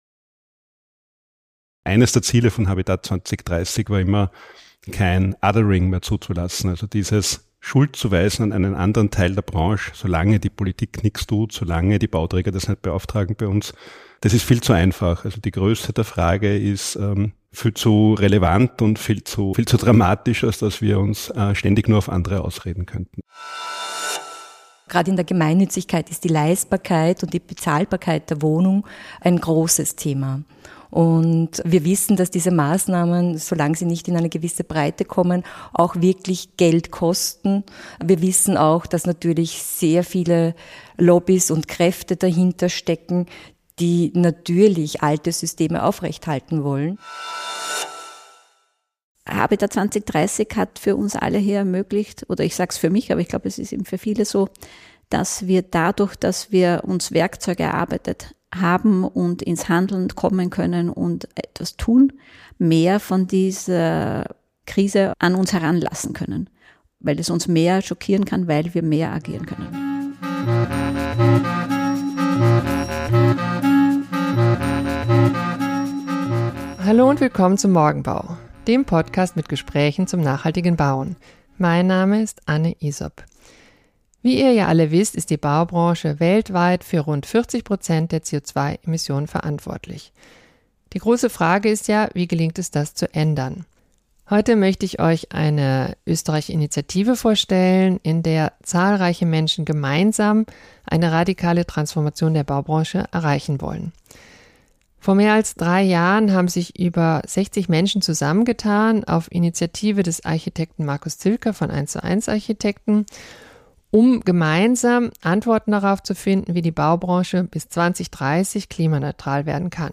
Im Gespräch mit drei Vertreter*innen der österreichischen Initiative Habitat 2030: Wie gelingt die radikale Transformation der Baubranche hin zur Klimaneutralität bis 2030?